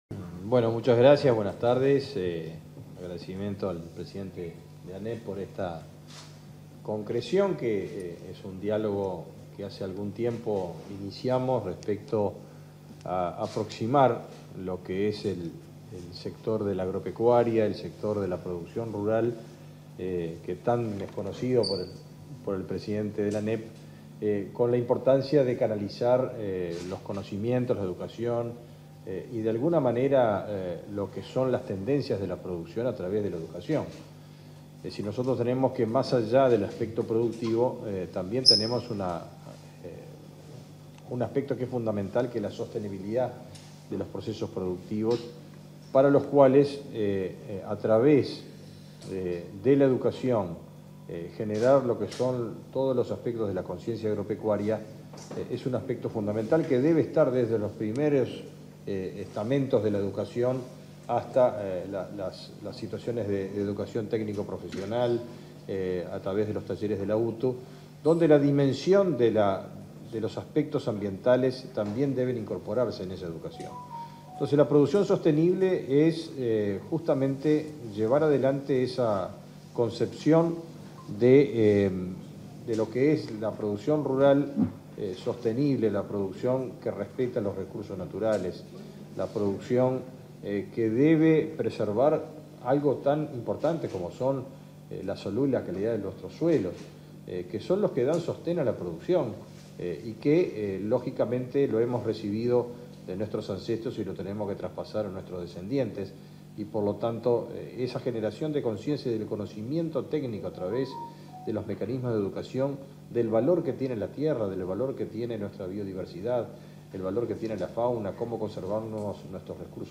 Firma de convenio de ANEP en cooperación con MGAP 08/09/2023 Compartir Facebook X Copiar enlace WhatsApp LinkedIn En el marco de la firma de un convenio marco de cooperación de la Administración Nacional de Educación Pública (ANEP) con el ministerio de Ganadería, Agricultura y Pesca (MGAP), se expresaron el presidente de la Administración Nacional de Educación Pública (ANEP), Robert Silva, y el ministro de Ganadería, Agricultura y Pesca (MGAP), Fernando Mattos.